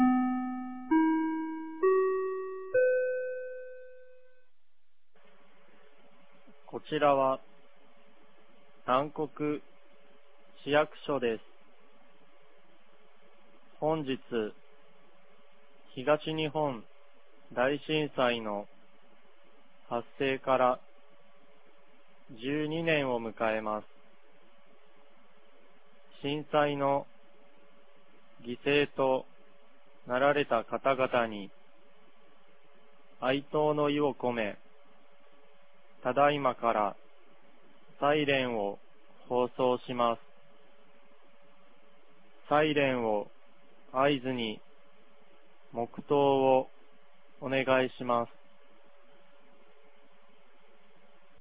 2023年03月11日 14時45分に、南国市より放送がありました。